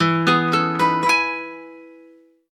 19_Acoustic_Guitar.ogg